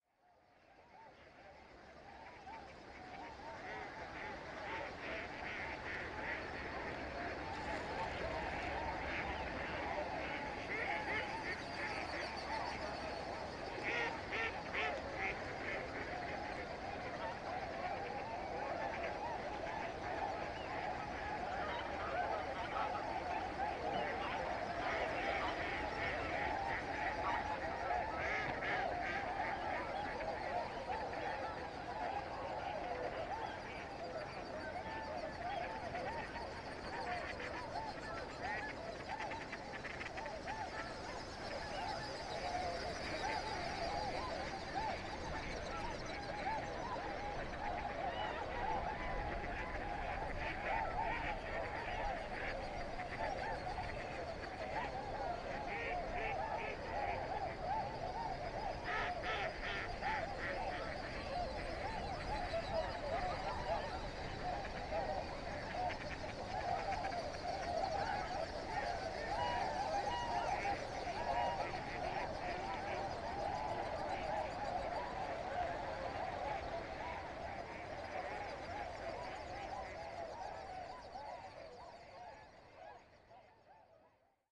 ZOOM H6, RODE NT5 （OMNI 20cm)2015年6月20日 千葉県
幼虫越冬のタンボコオロギは田植えが終わった頃には鳴き始める。ジッジッジ・・・と連続的に鳴き、ちょっとアマガエルの鳴き声に似た響きがある。
今回の録音ではアマガエルの声もたくさん入ってくる。その中でずっと連続的に続いて一度も止まらない鳴き声がタンボコオロギのものだ。マイクの近くにいる一匹の声をひろっている。